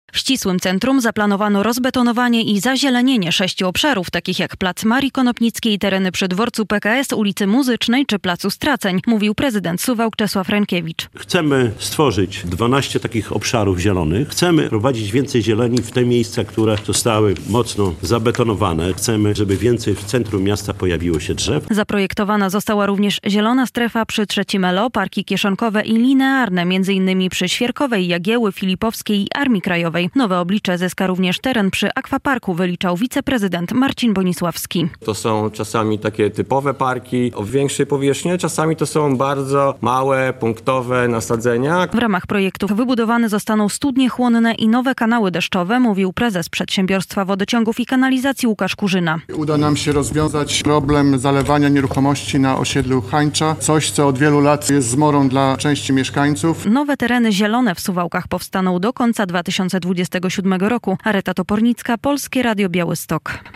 Projekt Zielone Suwałki - relacja